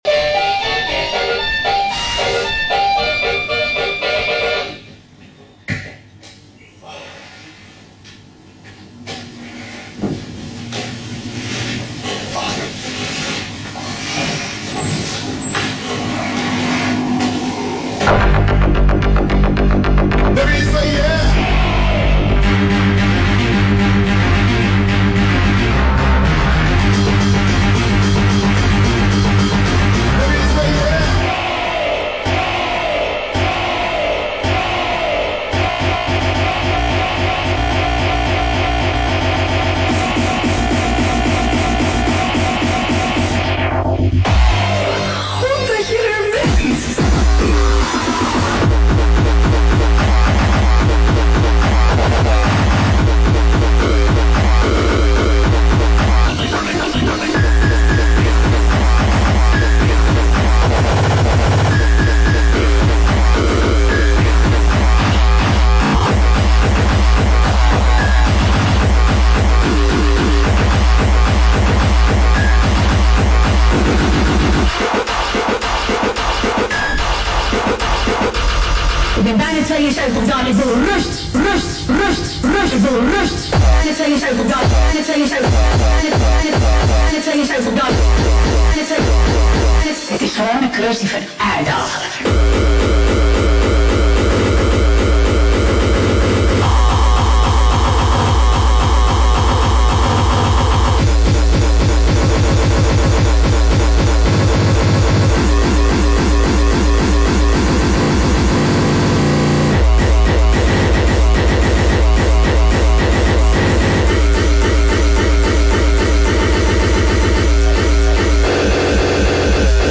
Live Set/DJ mix